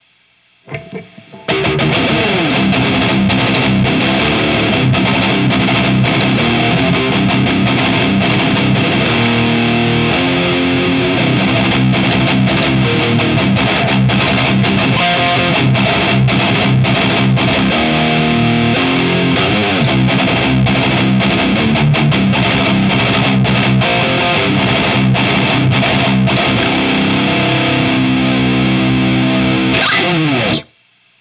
JCM2000のCLASSIC GAINでのサンプルです。
ZW-44とEMG81です。
コンプ感が強く好き嫌いがはっきり出るアンプです。